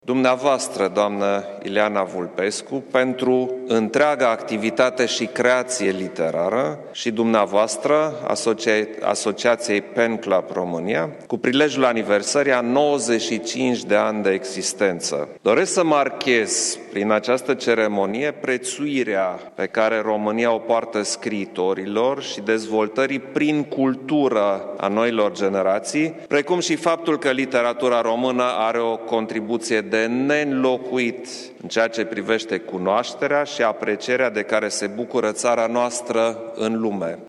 Președintele Klaus Iohannis, în timpul ceremoniei din Sala Unirii a Palatului Cotroceni: